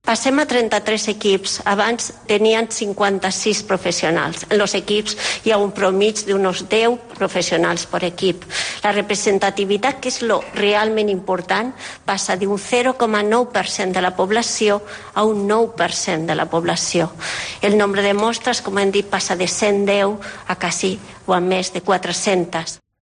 La secretaria de Salud Pública de la Generalitat, Carmen Cabezas, explica la importancia de este nuevo sistema